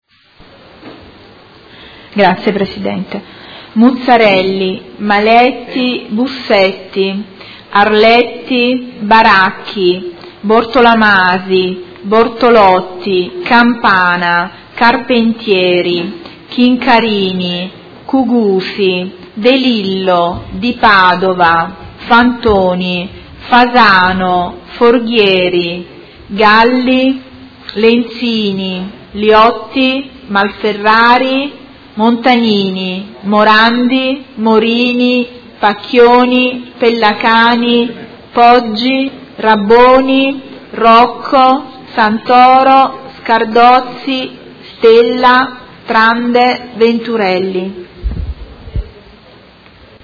Seduta del 15/03/2018. Appello